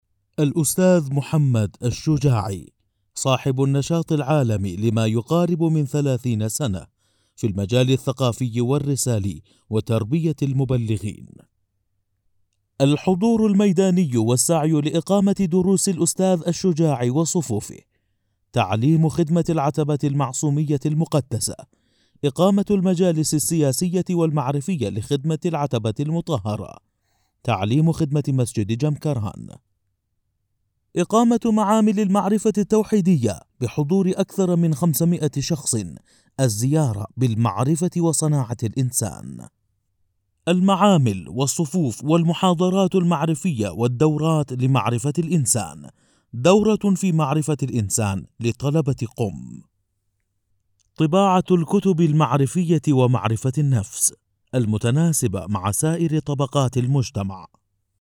Male
Adult
informative